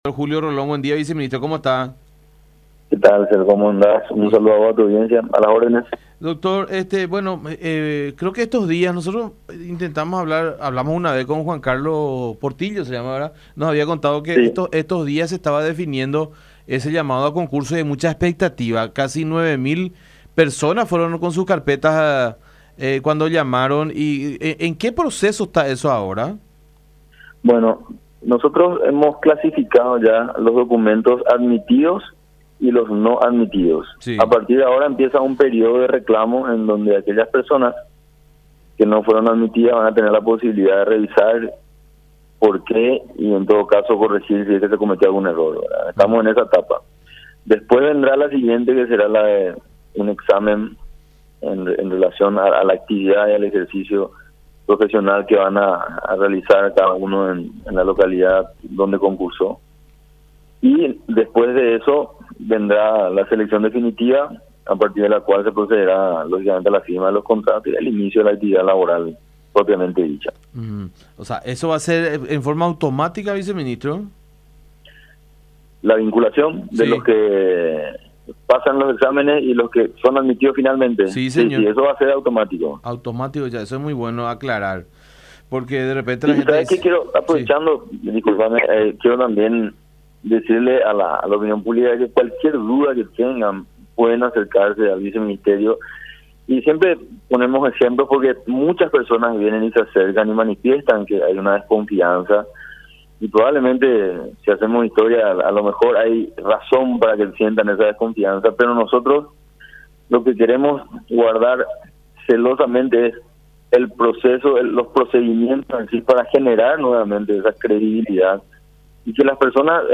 A partir de ahora comienza un periodo de reclamos en donde aquellas personas que no fueron admitidas tengan la posibilidad de hacer revisiones, explicó en comunicación con La Unión el Dr. Julio Rolón, viceministro de Salud Pública.